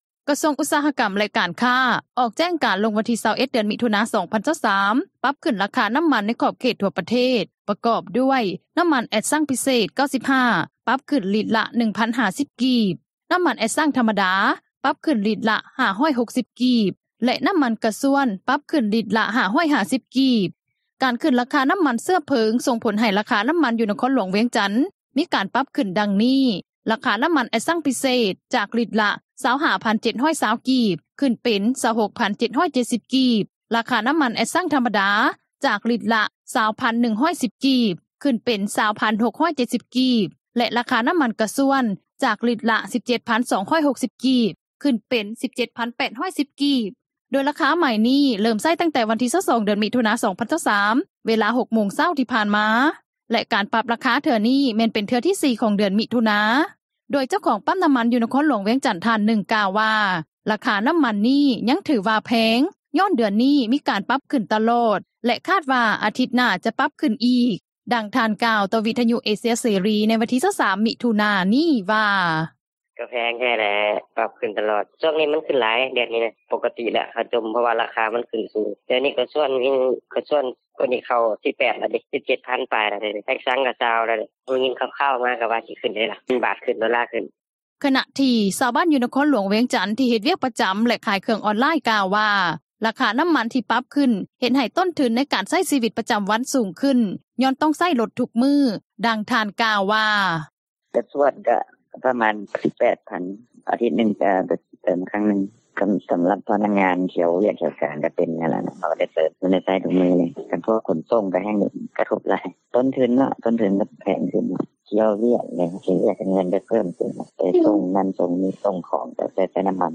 ຂຶ້ນລາຄານ້ຳມັນ ກະທົບພາກທຸຣະກິຈ-ພາກປະຊາຊົນ — ຂ່າວລາວ ວິທຍຸເອເຊັຽເສຣີ ພາສາລາວ
ດັ່ງຜູ້ປະກອບການ ຂັບຣົຖຕູ້ ຢູ່ນະຄອນຫຼວງວຽງຈັນ ທ່ານນຶ່ງ ກ່າວວ່າ:
ດັ່ງພໍ່ຄ້າ ຂາຍອາຫານທະເລ ຢູ່ແຂວງຄໍາມ່ວນ ທ່ານນຶ່ງ ກ່າວວ່າ: